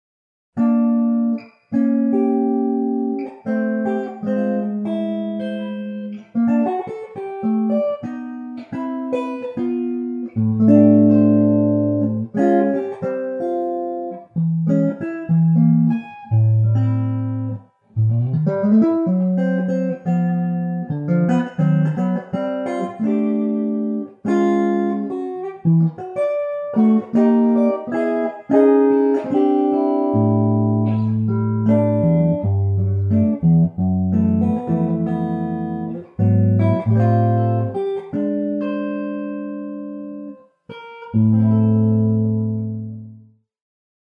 ES8] totalmente improvvisato dal sottoscritto che si basa proprio su questa ricerca di intervalli che prescinde totalmente dalle regole armoniche di ogni tipo, concretizzandosi in qualcosa di molto simile a "buttare le mani a caso sulla tastiera, ma con consapevolezza di quello che si sta facendo".